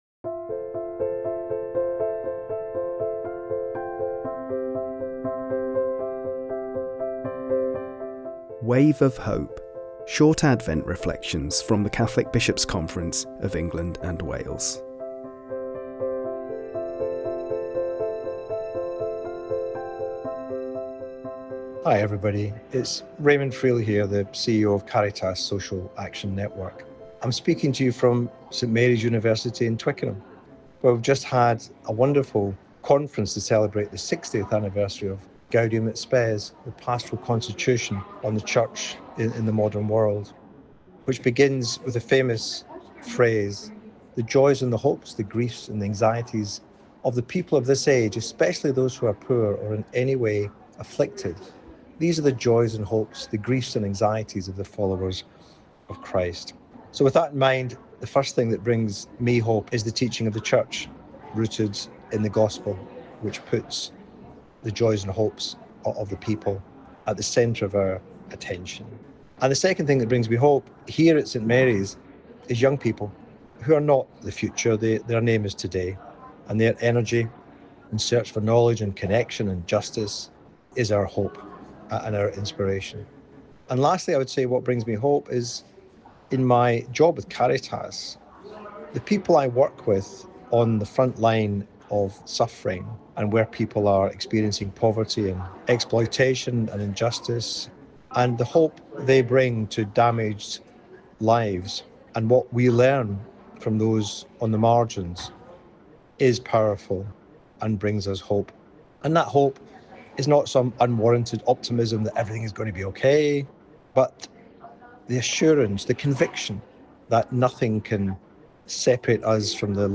Speaking from St Mary’s University, Twickenham after the recent conference marking the 60th anniversary of Gaudium et Spes